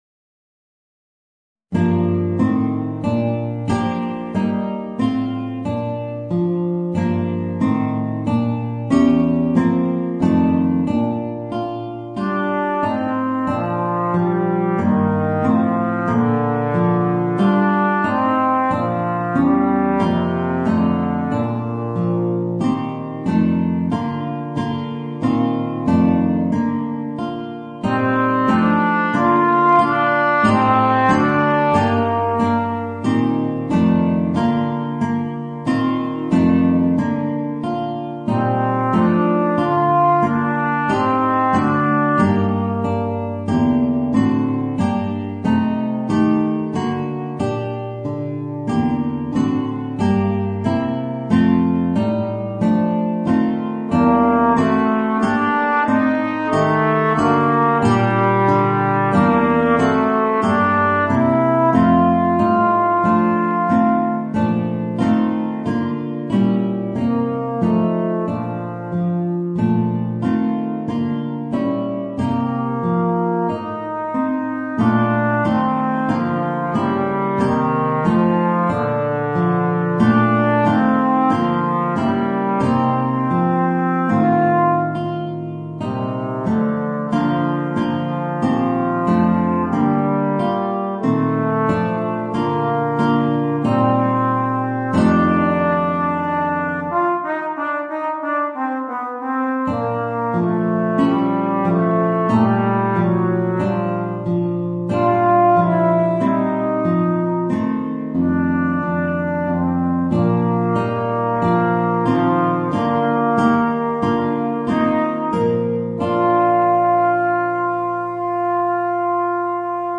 Voicing: Trombone and Guitar